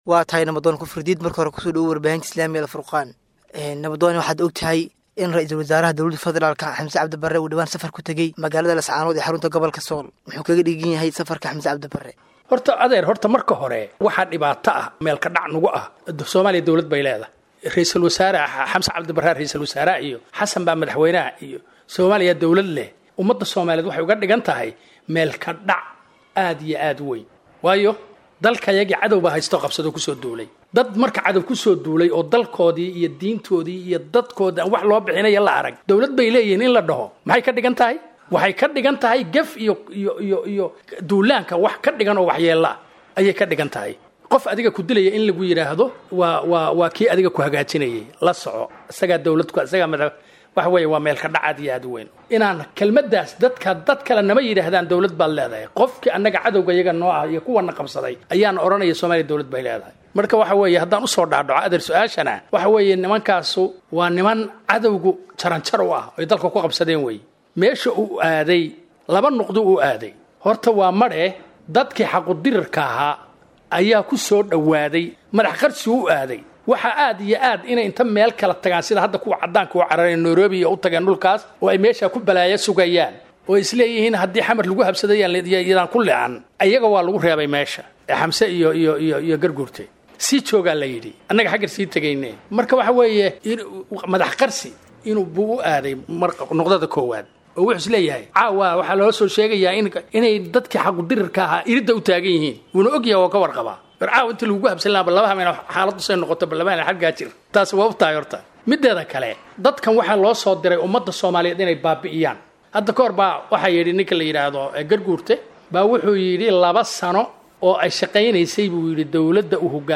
Muxuu Salka ku Hayaa Safarka Xamse Cabdi Barre ee Laascaanood?[WAREYSI]